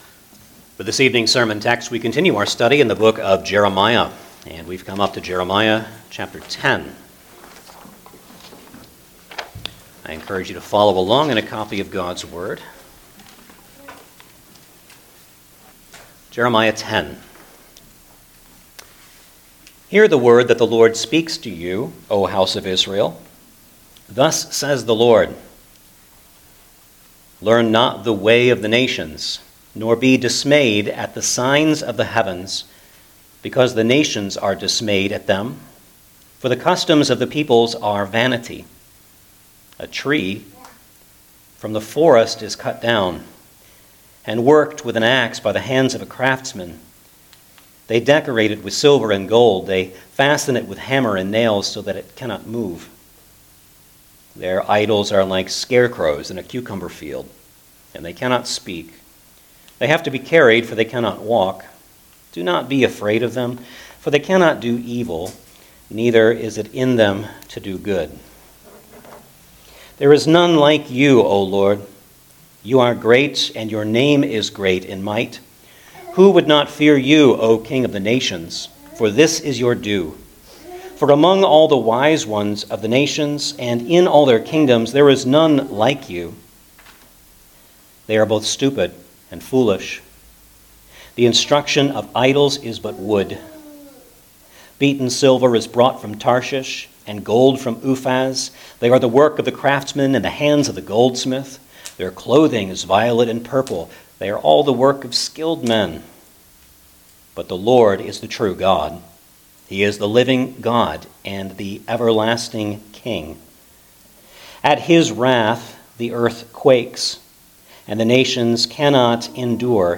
Jeremiah Passage: Jeremiah 10 Service Type: Sunday Evening Service Download the order of worship here .